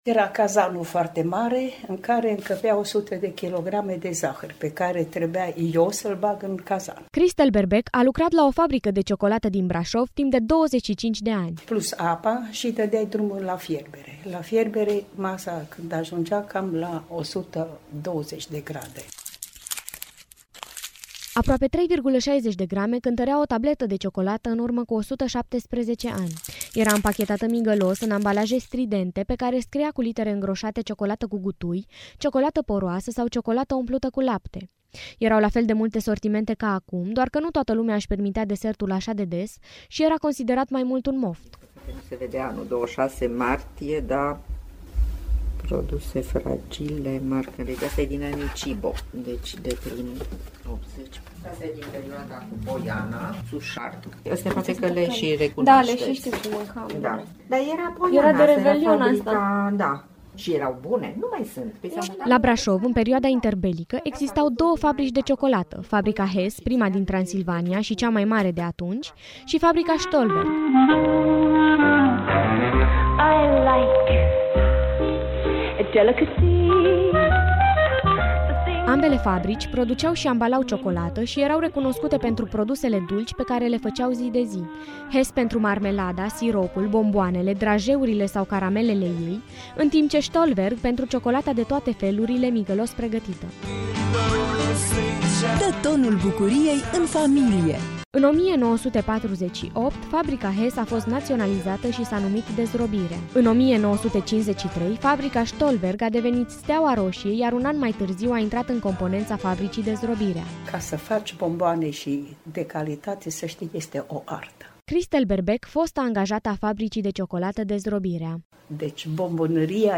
Reportajul-cu-ciocolata.mp3